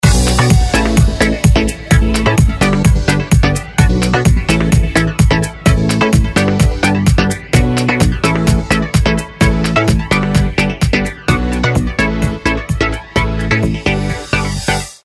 رینگتون ورزشی موبایل